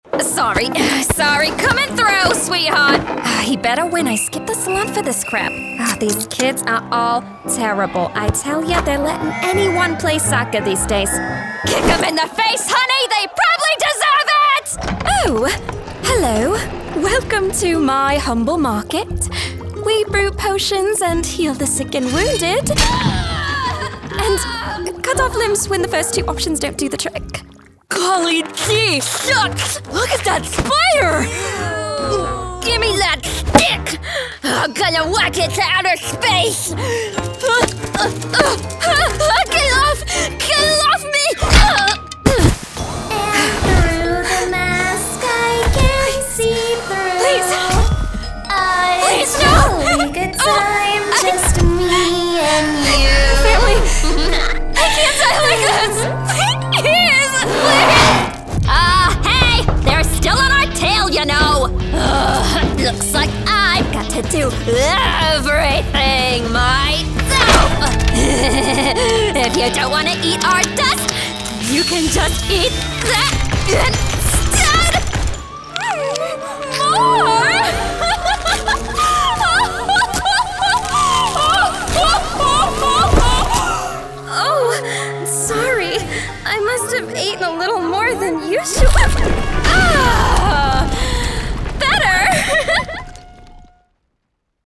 English (American)
Commercial, Young, Natural, Distinctive, Versatile